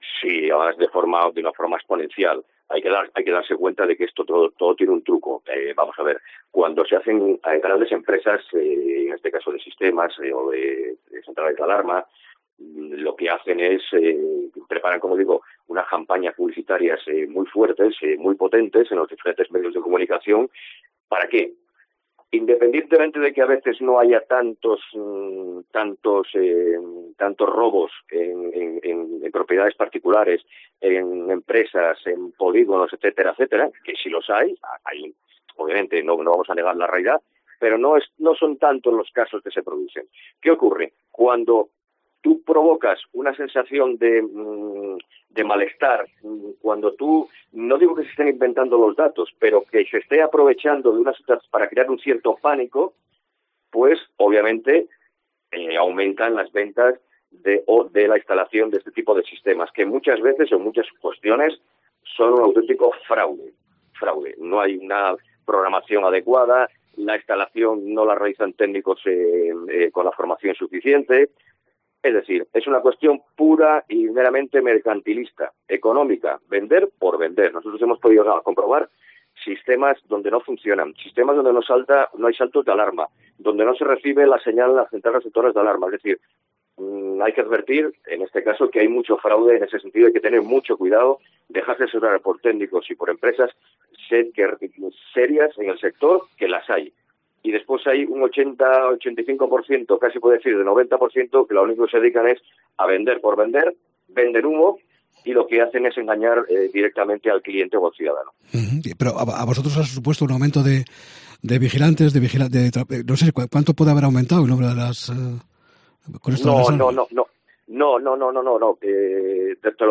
Declaraciones de AVISPA sobre la instalación de alarmas en Asturias